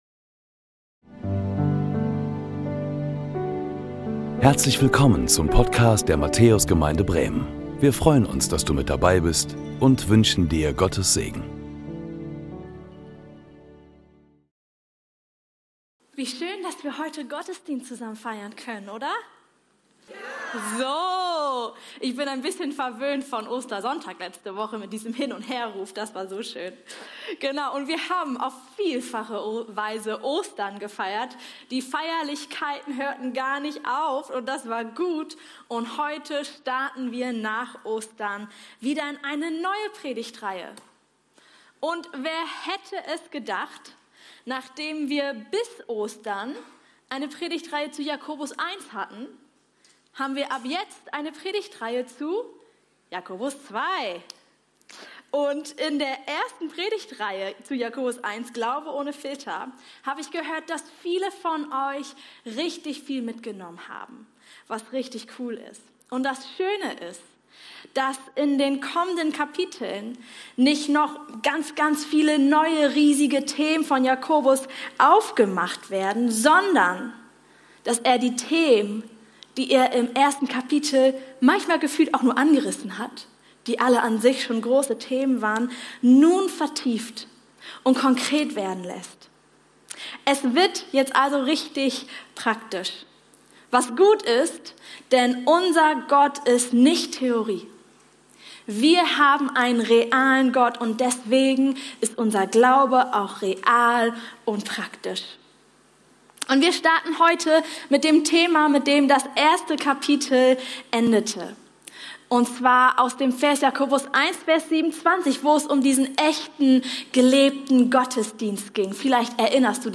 Willkommen zu unserem 10 Uhr Gottesdienst aus der Matthäus Gemeinde Bremen!